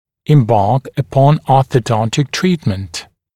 [ɪm’bɑːk ə’pɔn ˌɔːθə’dɔntɪk ‘triːtmənt] [em-][им’ба:к э’пон ˌо:сэ’донтик ‘три:тмэнт] [эм-]приступить к ортодонтическому лечению